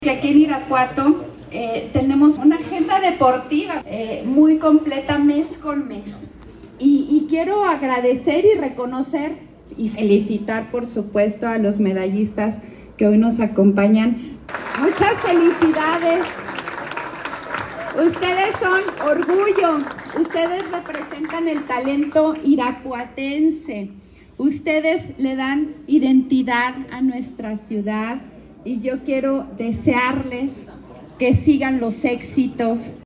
Valeria Alfaro García, presidenta del Sistema DIF Municipal